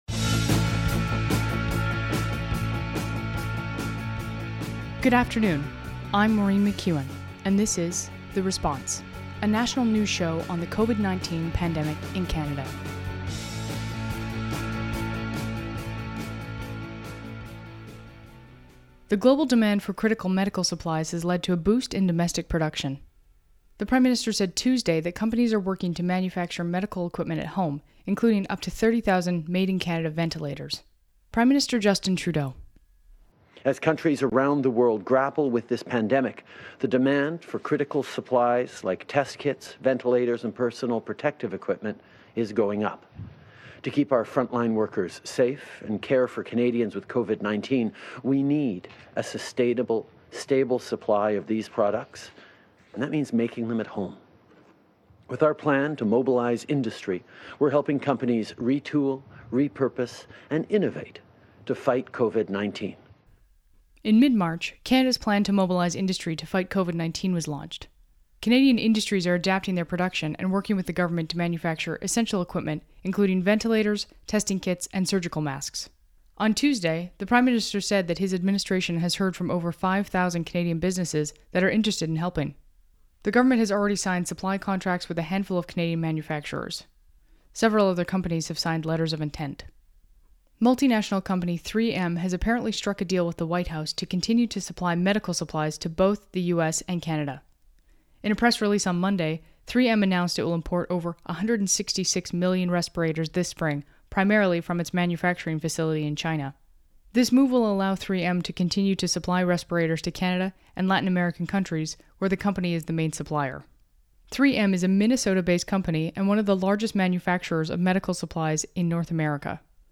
National News Show on COVID-19
Credits: Audio clips: Canadian Public Affairs Channel.
Type: News Reports
192kbps Stereo